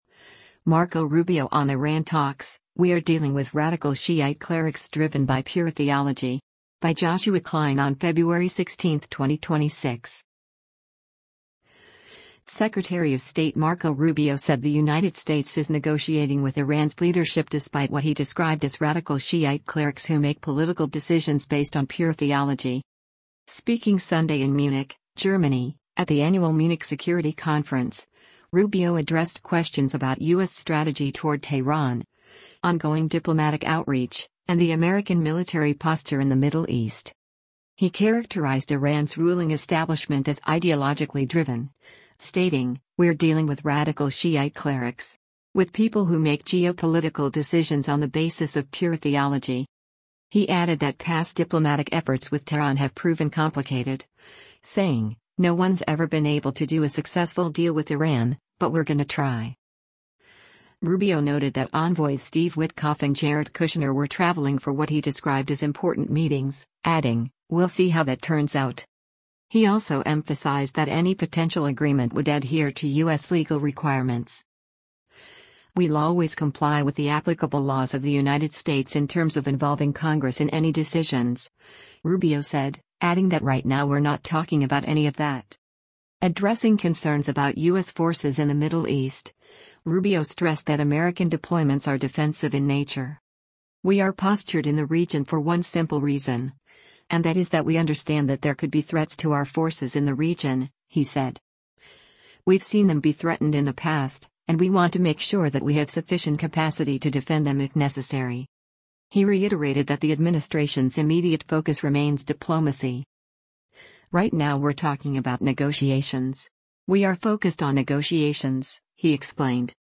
Speaking Sunday in Munich, Germany, at the annual Munich Security Conference, Rubio addressed questions about U.S. strategy toward Tehran, ongoing diplomatic outreach, and the American military posture in the Middle East.